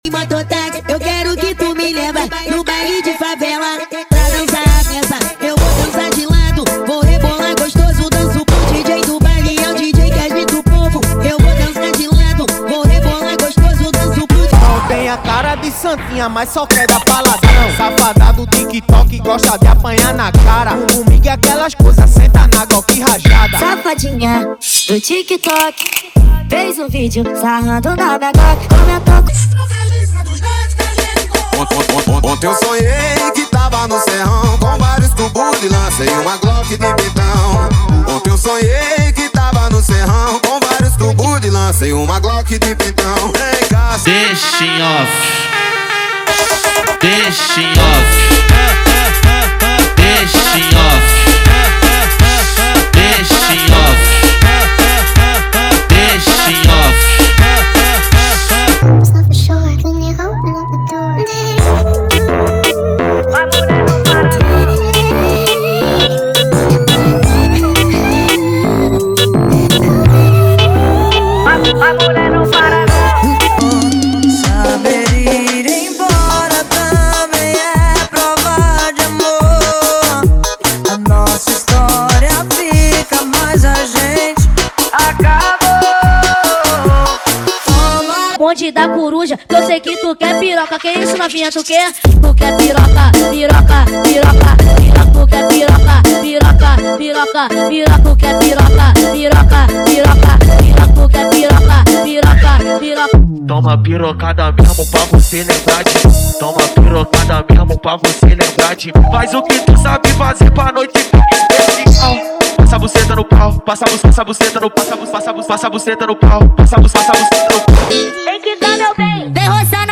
• Brega Funk = 50 Músicas